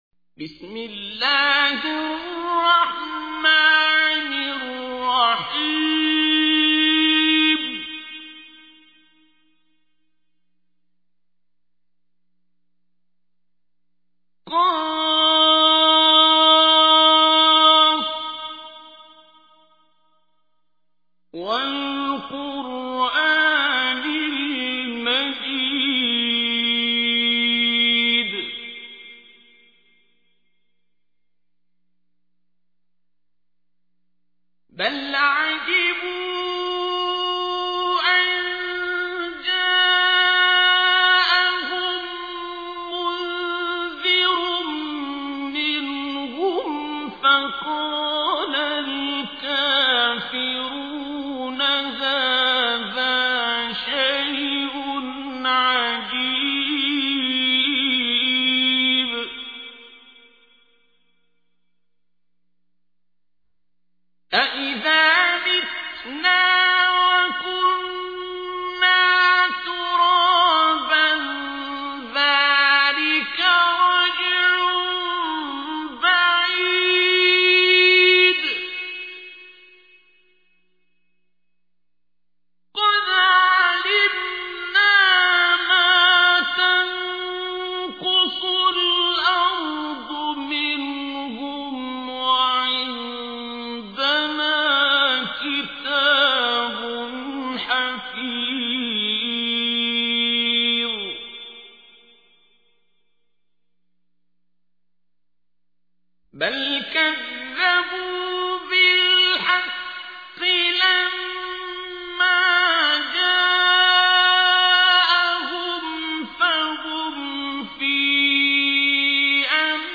تحميل : 50. سورة ق / القارئ عبد الباسط عبد الصمد / القرآن الكريم / موقع يا حسين